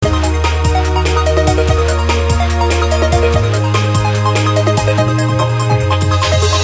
beepy piano track (1992)